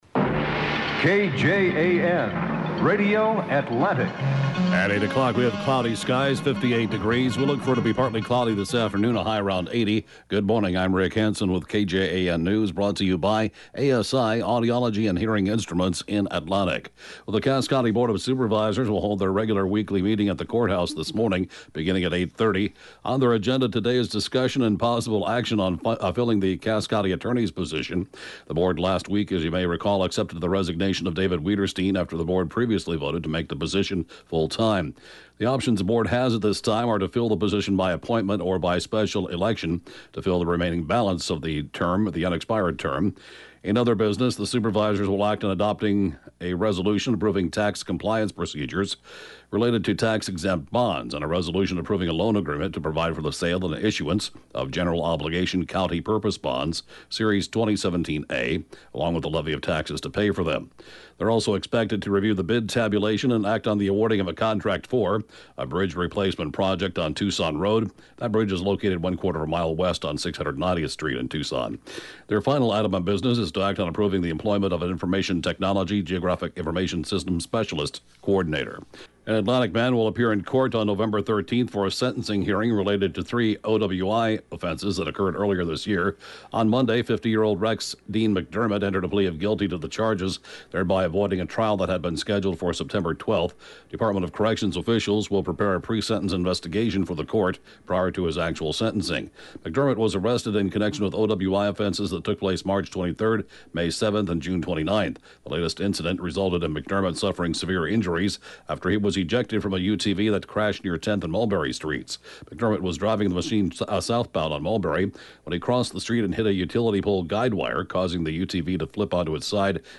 News, Podcasts